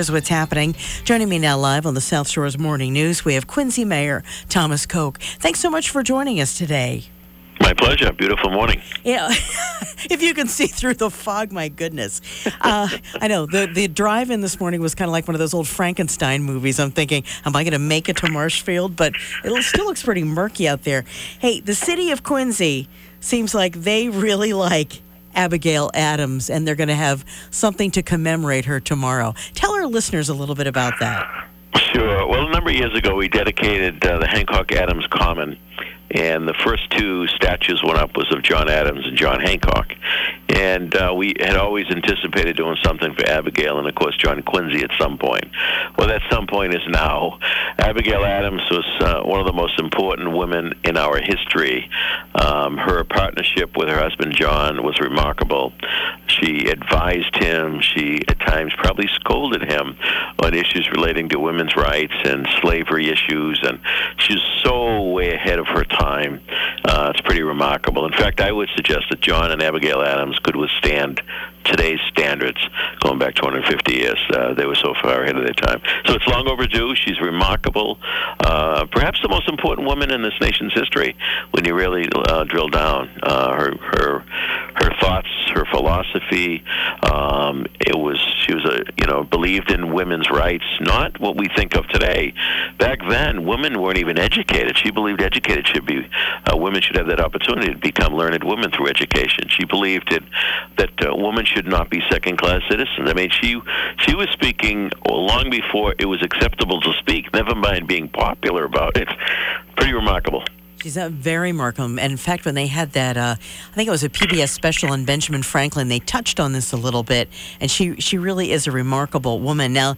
Mayor Tom Koch joined WATD to discuss the event, which takes place Saturday at 11 a.m. at the Hancock Adams Common, 1305 Hancock Street in Quincy.